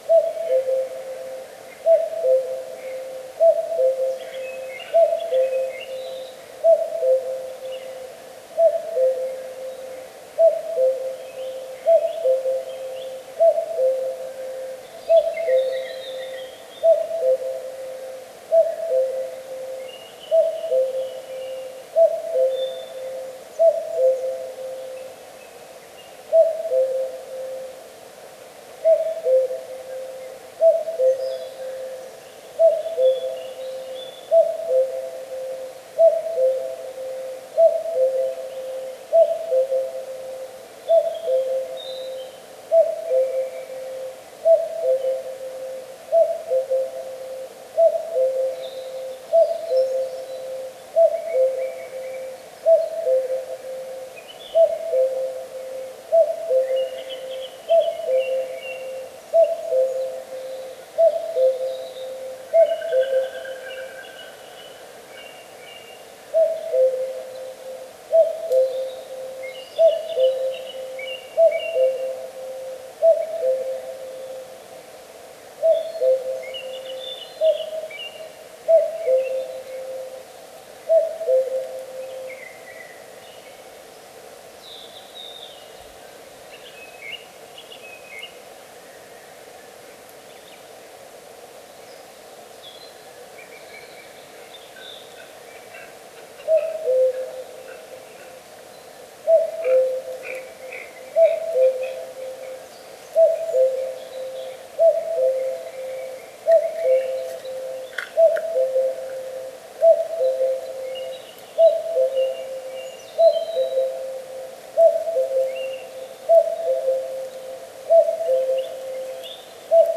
Птицы -> Кукушковые ->
кукушка, Cuculus canorus
СтатусПоёт